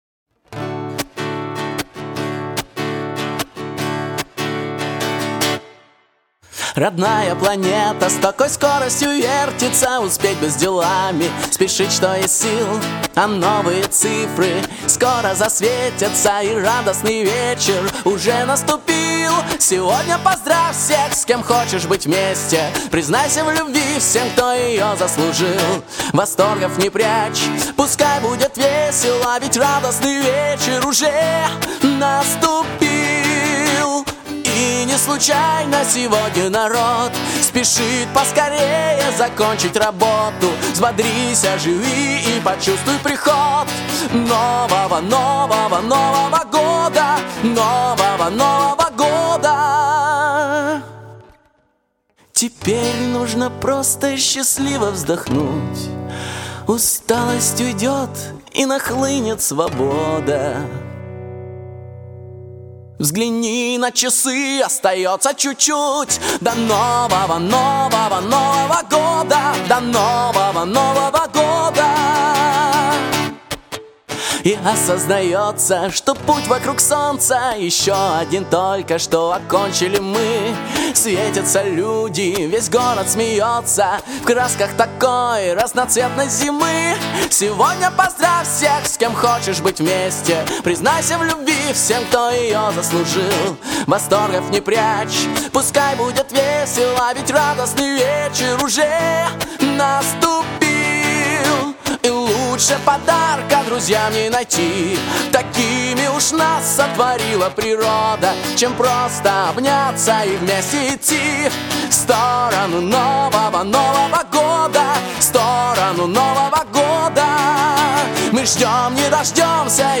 на мои стихи и мой мотив, аранжировано и сыграно группой МИРУМИР г.Йошкар-Ола